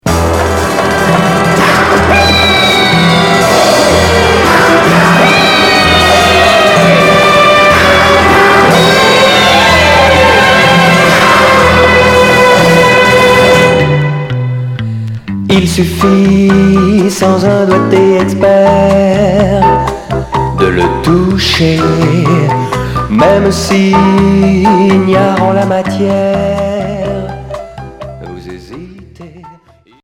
Pop jerk psyché